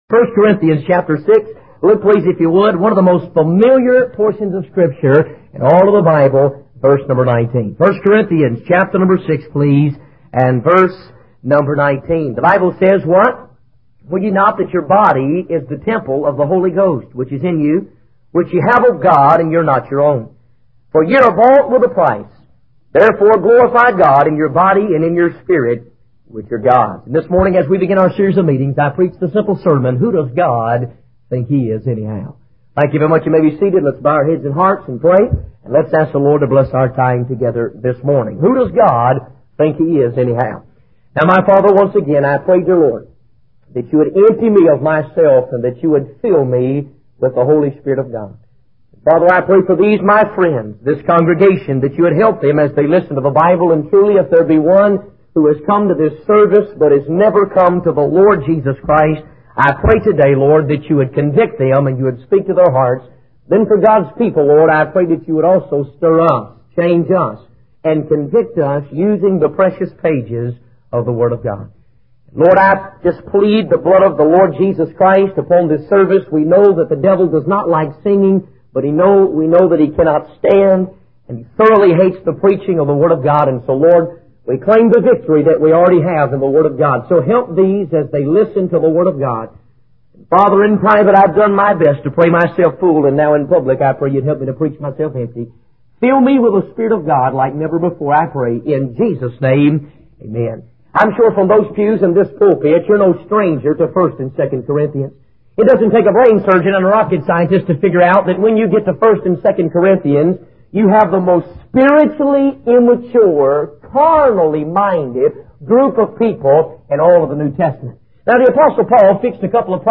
In this sermon, the preacher emphasizes that God owns us by right of creation.